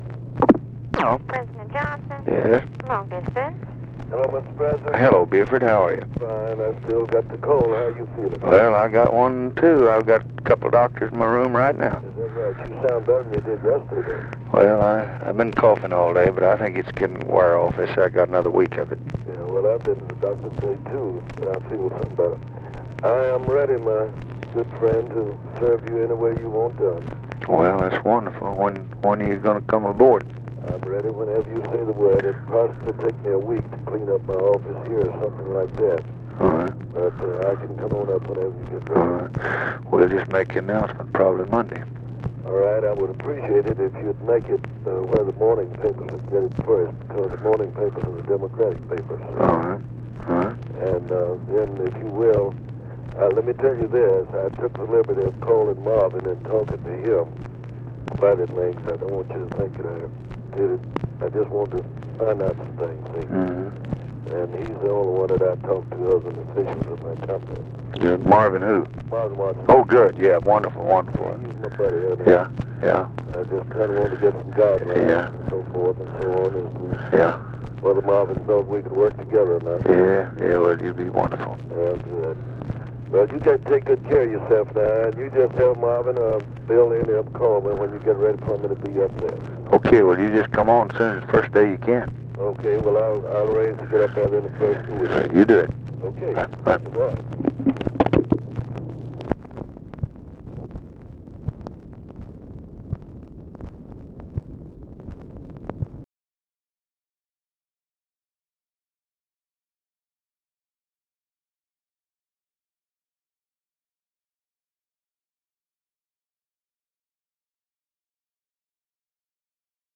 Conversation with BUFORD ELLINGTON, January 30, 1965
Secret White House Tapes